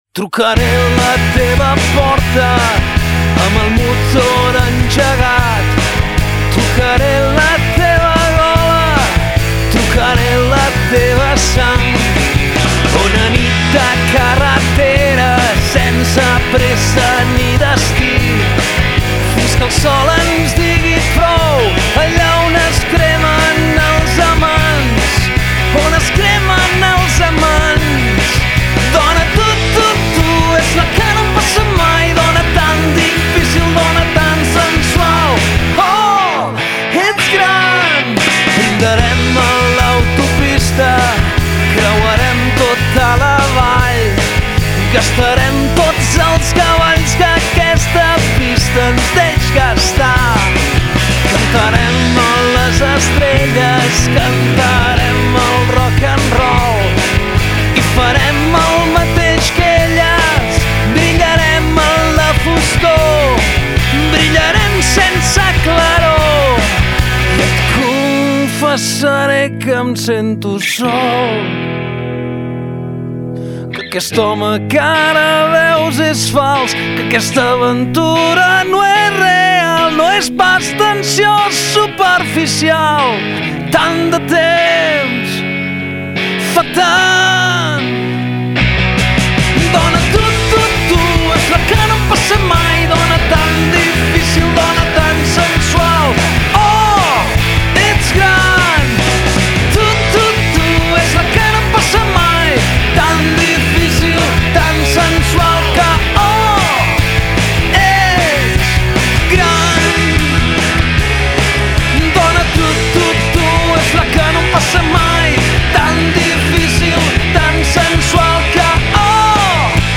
Són noves cançons que aposten per la senzillesa